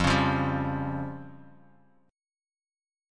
Fsus2#5 chord